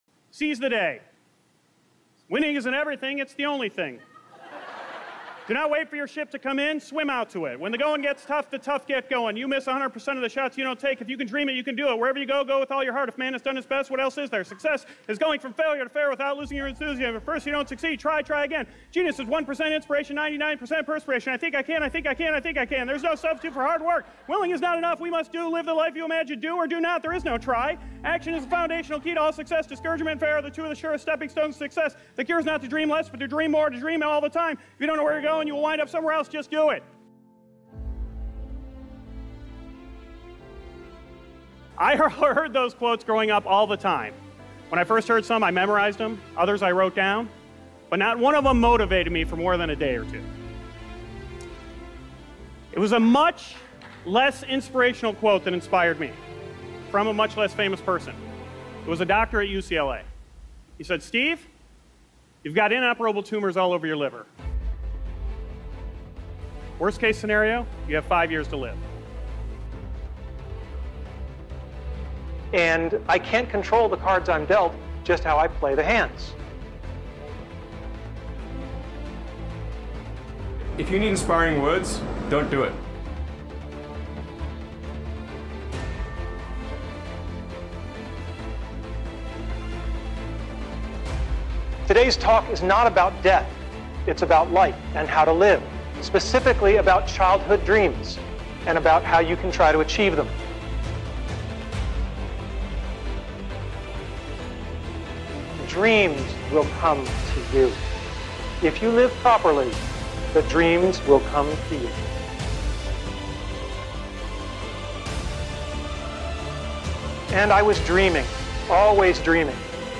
Inspiring Speech on Purpose: A Single Insight That Sparks Lasting Change